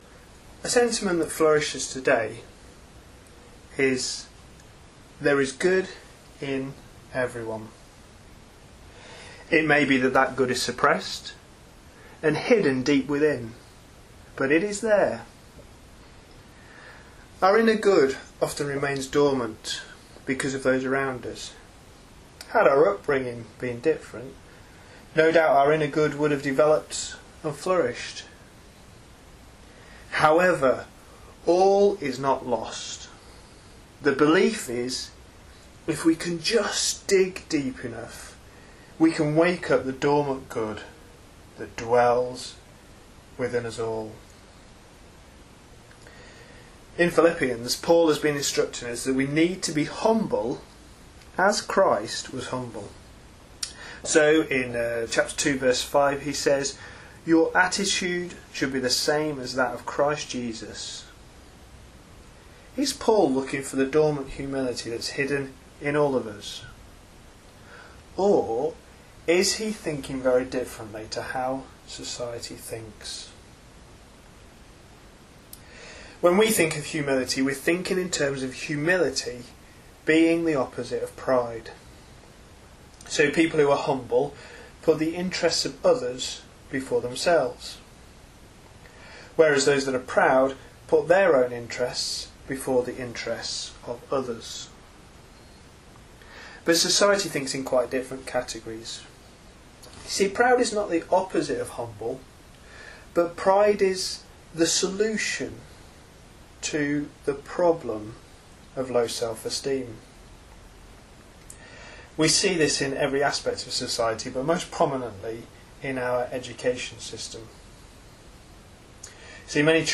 A sermon preached on 12th August, 2012, as part of our Philippians series.